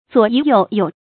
左宜右有 zuǒ yí yòu fú
左宜右有发音